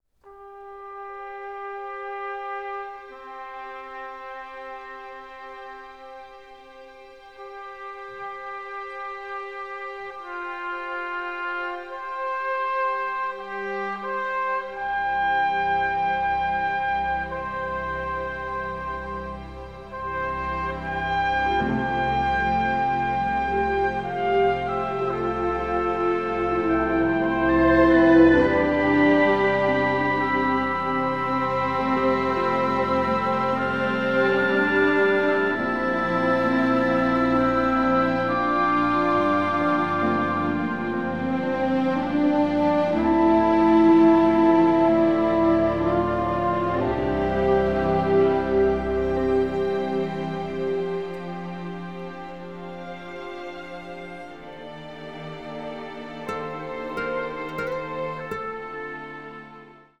emotional, symphonic Americana score